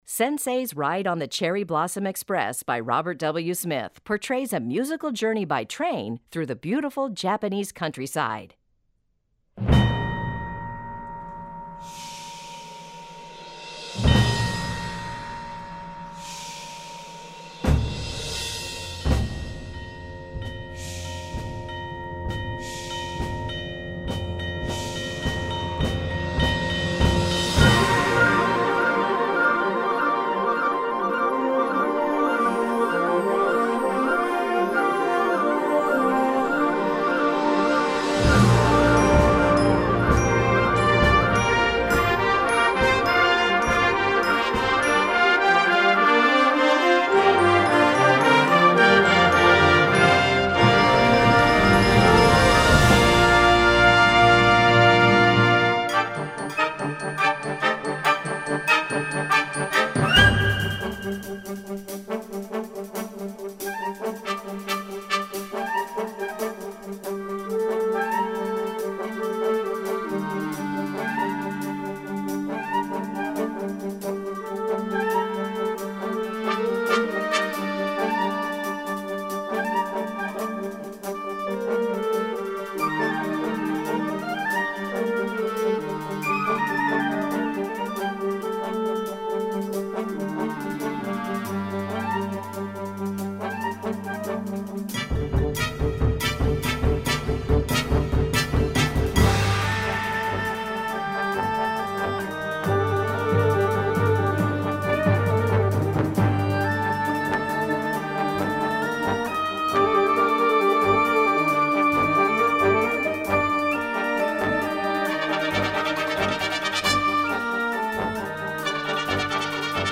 Categorie Harmonie/Fanfare/Brass-orkest
Subcategorie Concertmuziek
Bezetting Ha (harmonieorkest)
Bandsmen vocalizing